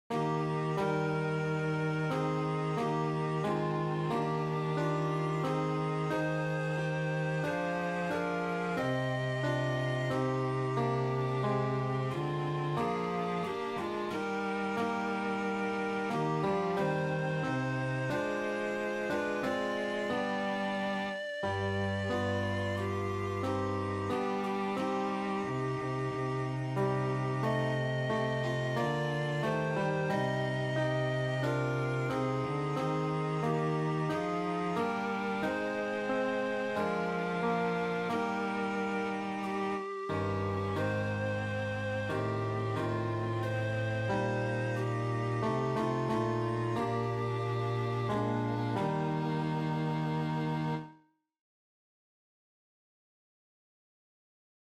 Chorproben MIDI-Files 508 midi files